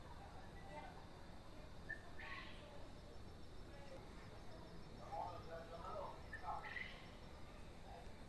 Rufous Nightjar (Antrostomus rufus)
Country: Argentina
Province / Department: Misiones
Condition: Wild
Certainty: Recorded vocal